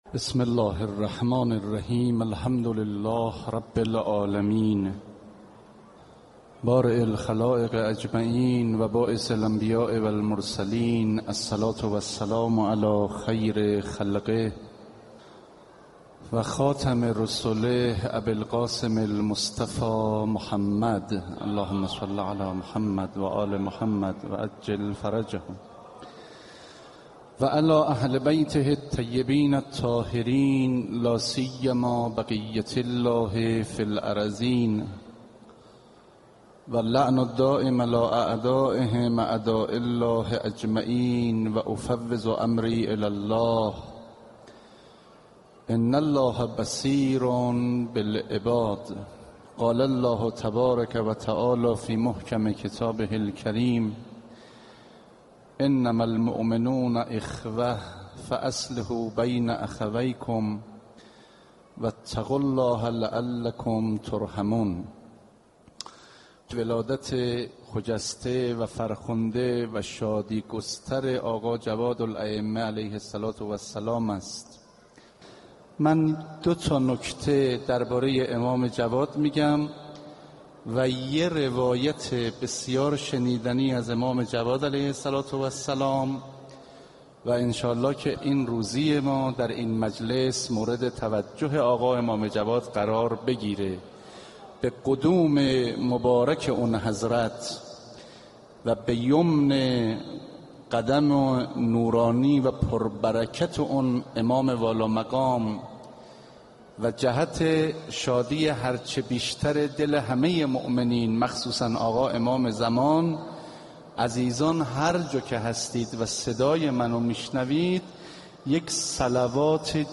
دانلود سخنرانی تفاوت امام با انسان های معمولی